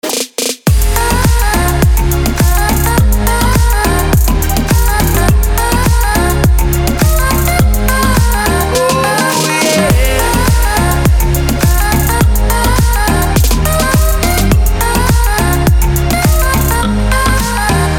• Качество: 320, Stereo
dancehall
Electronic
club
house